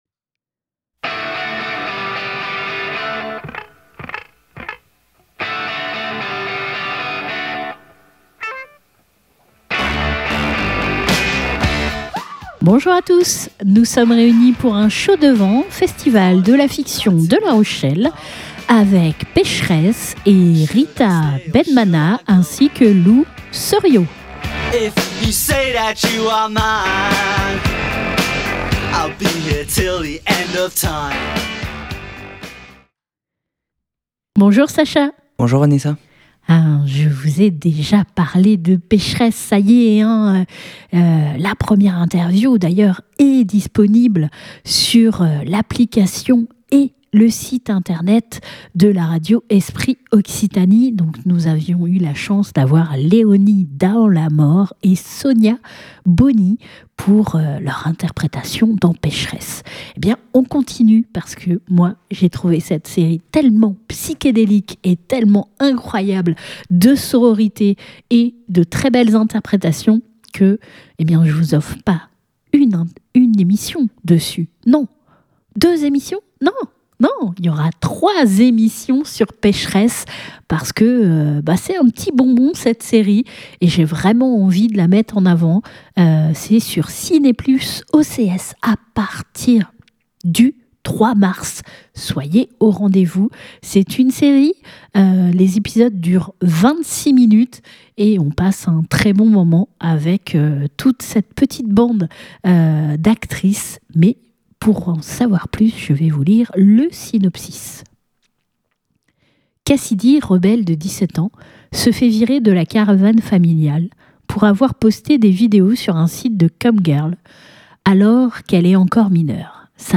20 février 2026 Écouter le podcast Télécharger le podcast Lors du festival de la fiction de La Rochelle nous avons pu découvrir en avant-première la série "Pécheresses" une série ovni de sororité où l'amitié et la quête de soi sont essentielles.